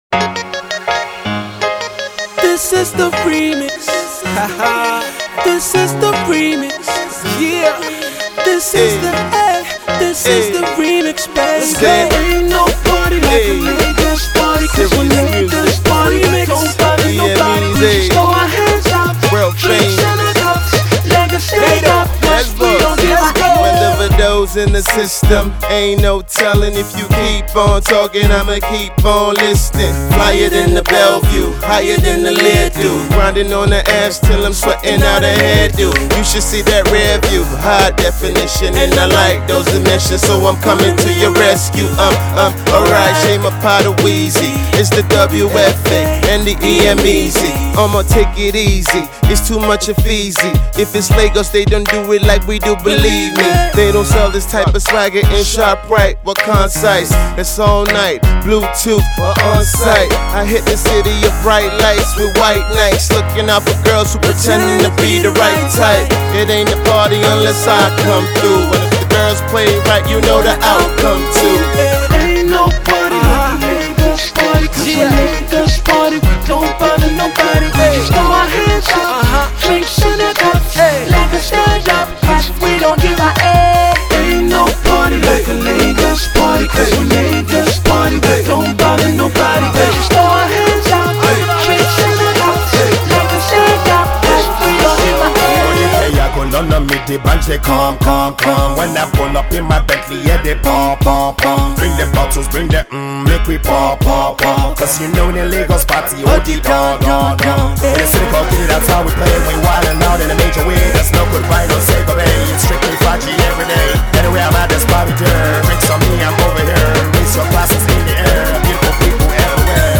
all-star remix
RnB music